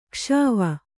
♪ kṣāva